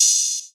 DDW Open Hat 1.wav